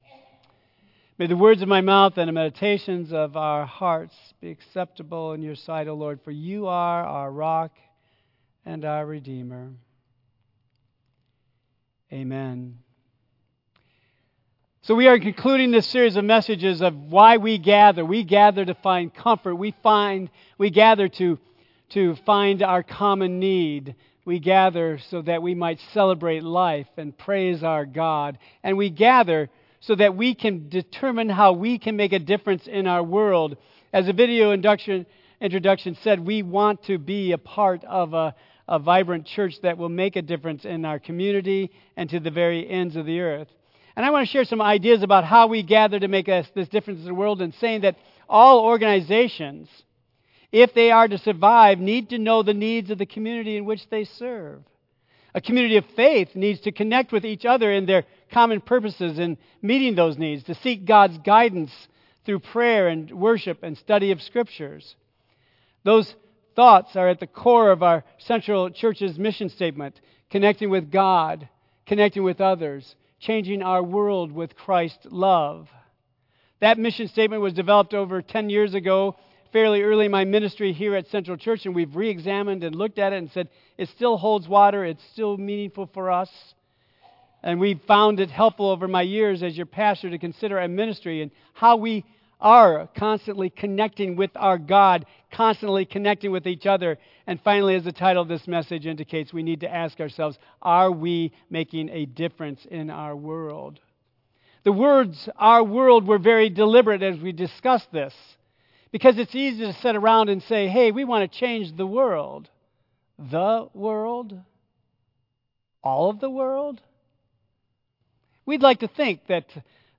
Tagged with Central United Methodist Church , Michigan , Sermon , Waterford , Worship Audio (MP3) 5 MB Previous We Gather...To Find Comfort Next Who Are We Going to Be?